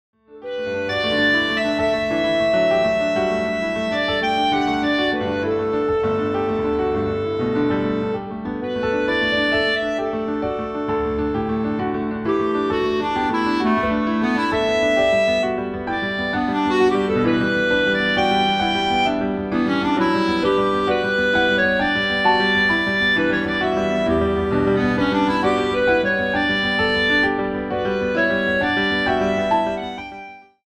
für Klarinette und Klavier
Beschreibung:Klassik; Kammermusik
Besetzung:Klarinette in B, Klavier